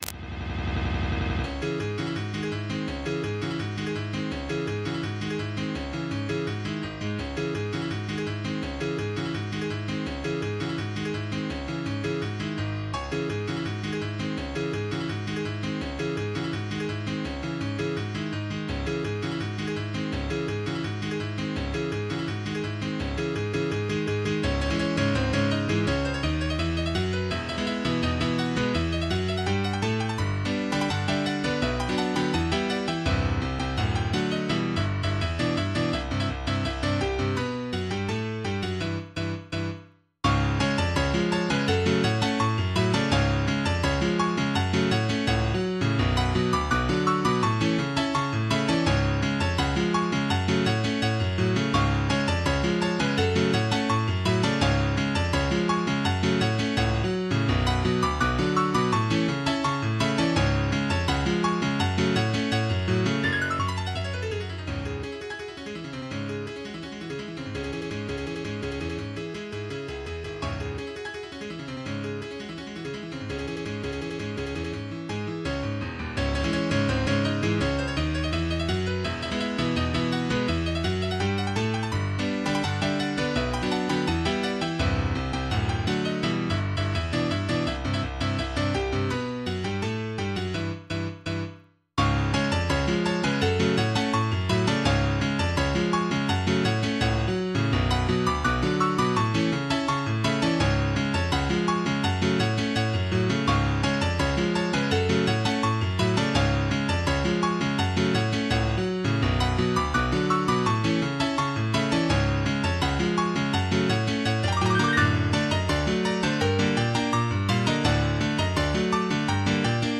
Karaoke Tracks
MP3 (Converted)